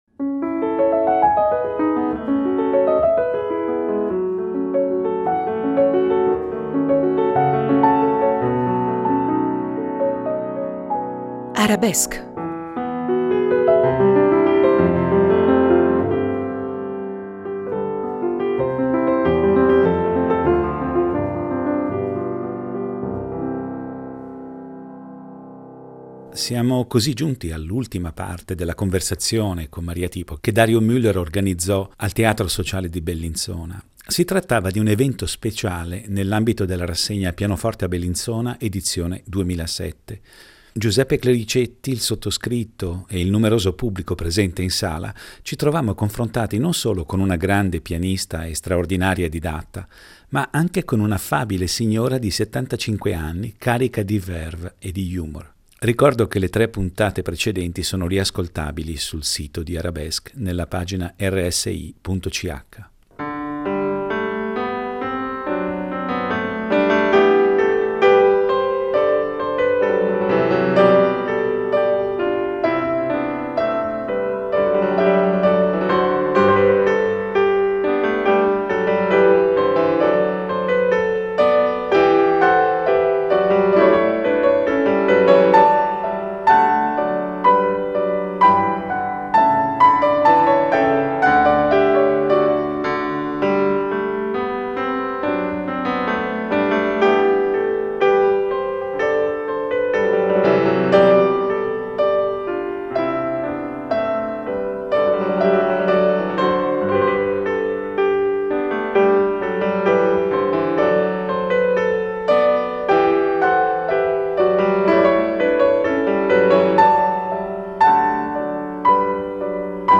Al pubblico presente a questo evento veniva data la possibilità di incontrare e conoscere la celebre pianista in una conversazione estemporanea